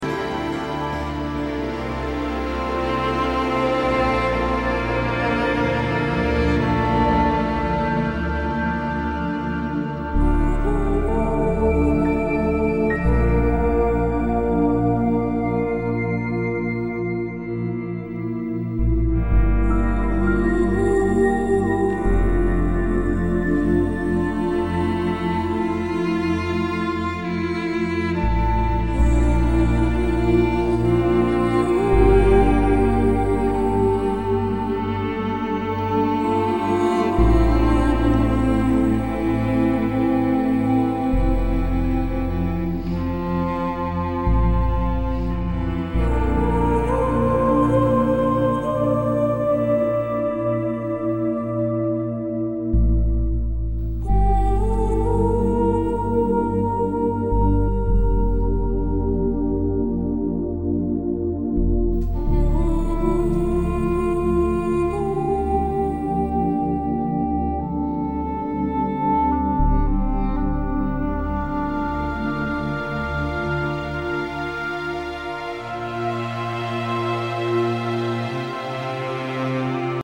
The mysterious and bone-chilling score